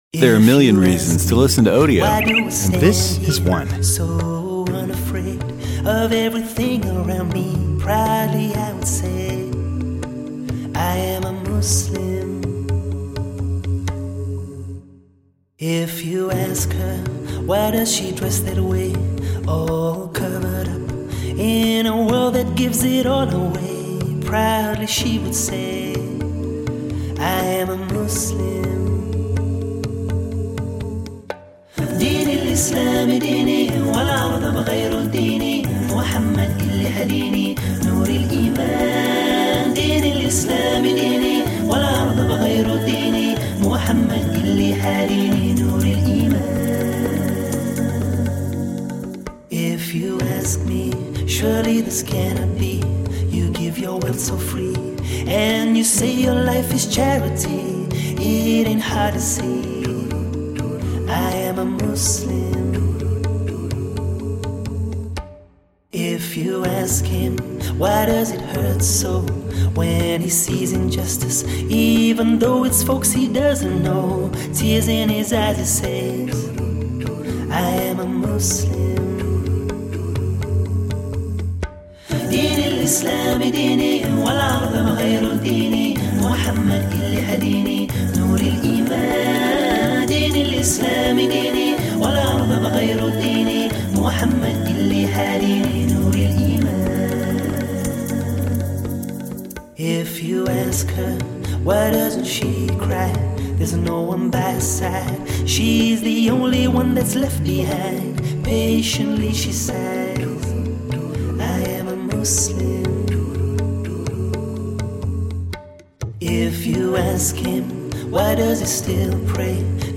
who has achieved fame as a performer of nasheed songs.